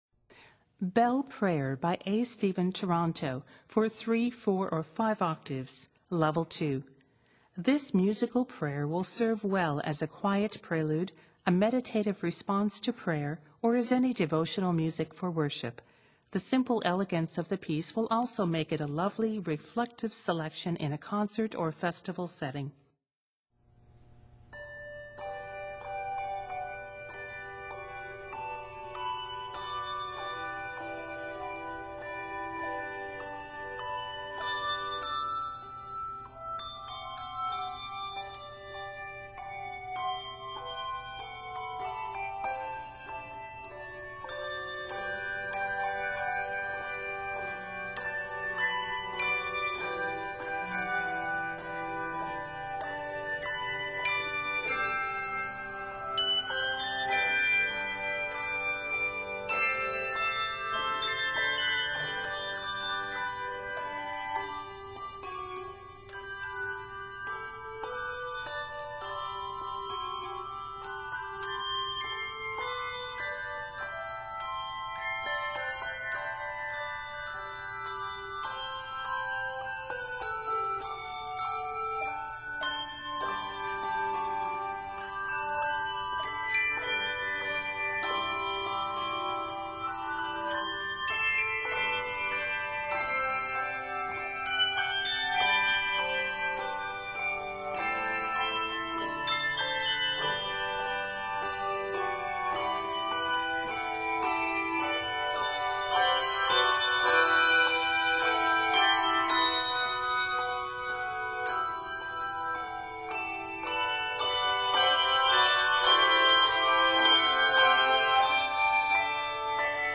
It is set in G Major.